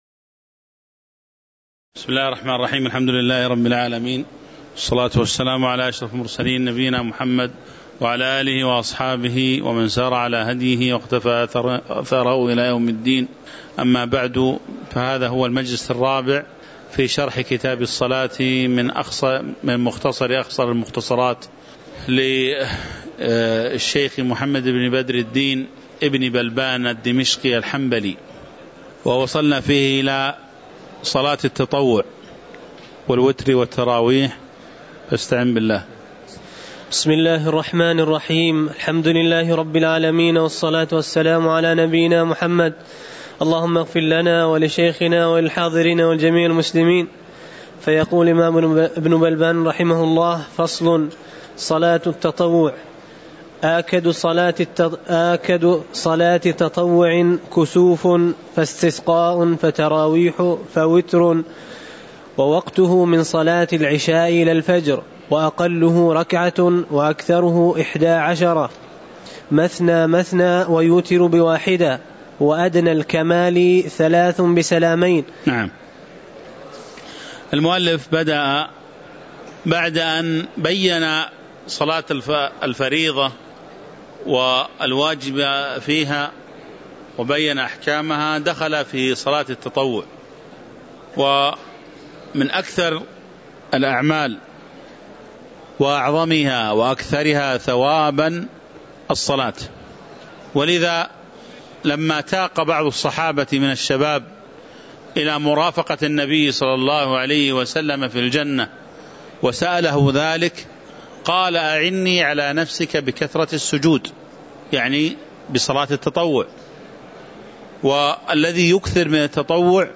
تاريخ النشر ١٦ ربيع الثاني ١٤٤١ هـ المكان: المسجد النبوي الشيخ